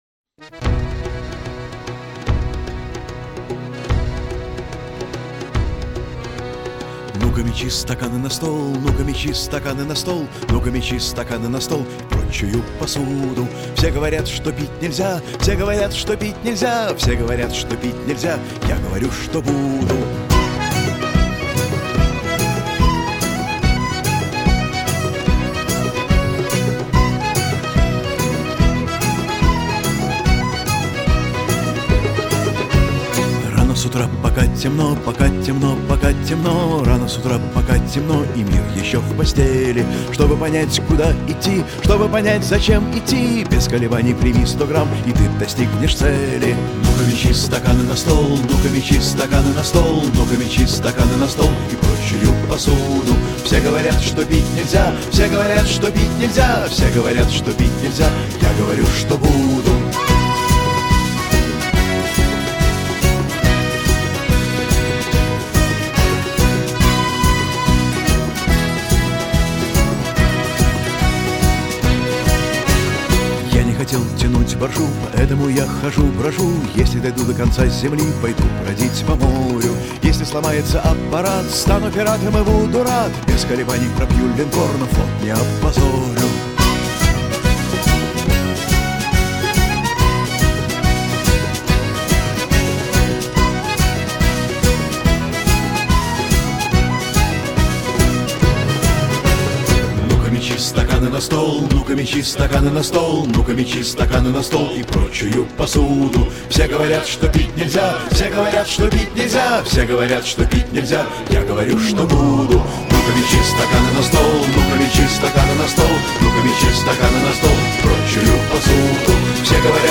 Вчера ходил в зал консерватории.